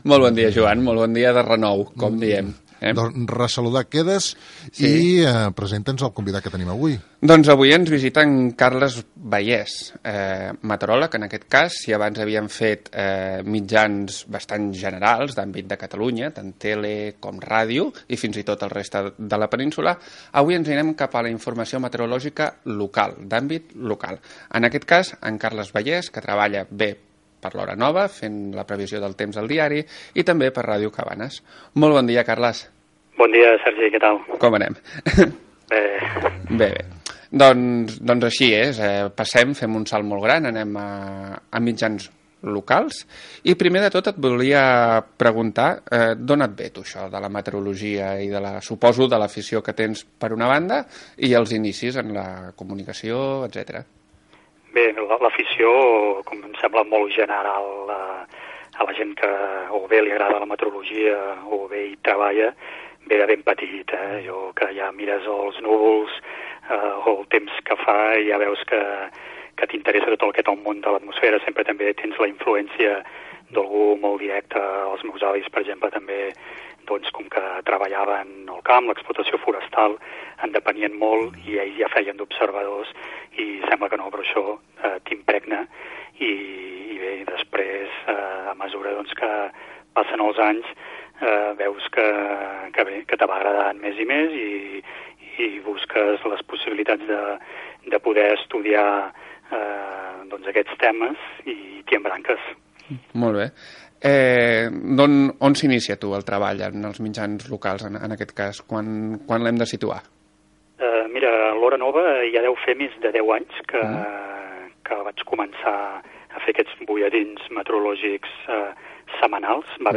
Gènere radiofònic Info-entreteniment